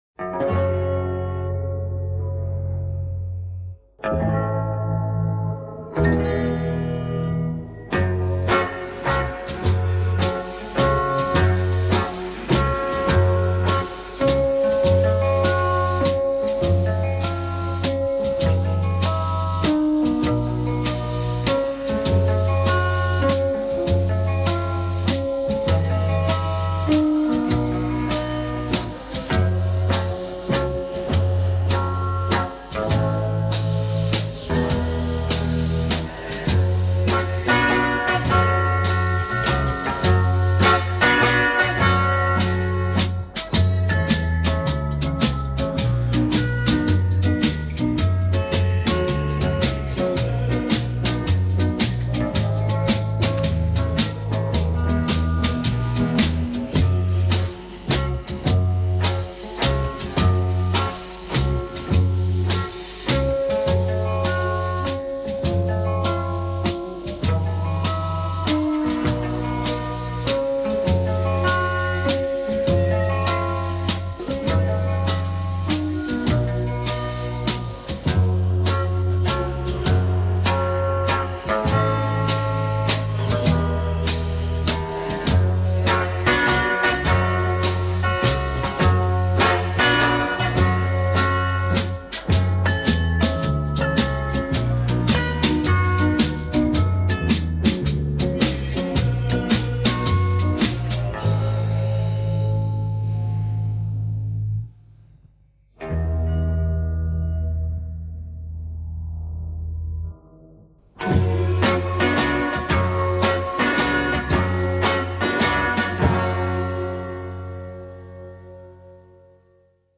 Прослушайте мелодию.